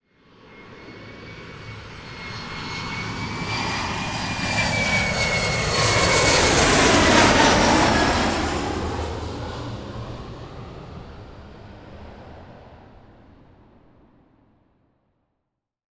دانلود آهنگ سوت هواپیما 6 از افکت صوتی حمل و نقل
دانلود صدای سوت هواپیما 6 از ساعد نیوز با لینک مستقیم و کیفیت بالا
جلوه های صوتی